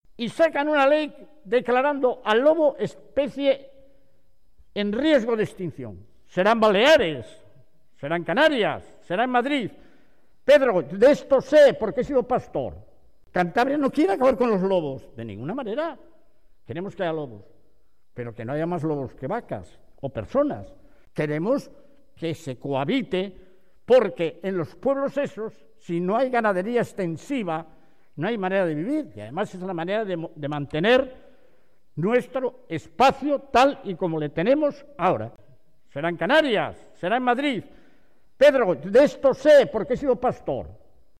“Hay cosas que tendrían que cambiar para que nos sintiéramos todos partícipes de un país sin privilegios para unos y castigos para otros”, señala el presidente cántabro en la apertura del Diálogo para el Futuro del Trabajo.